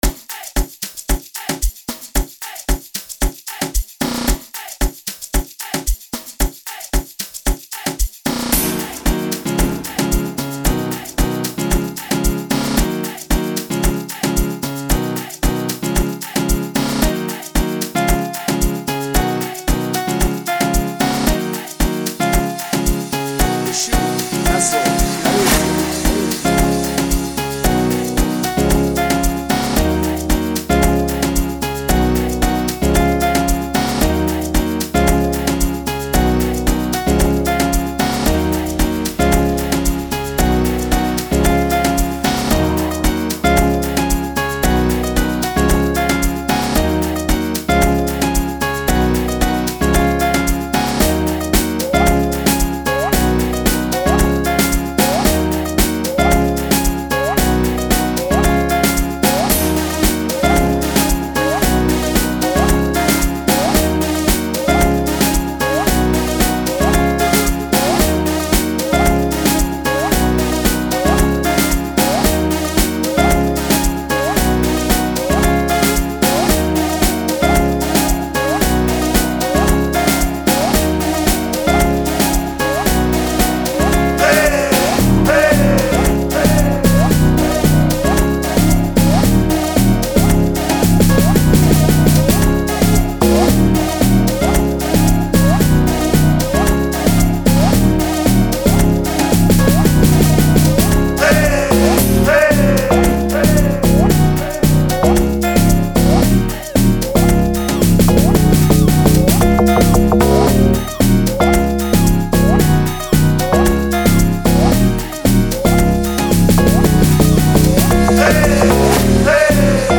04:04 Genre : Amapiano Size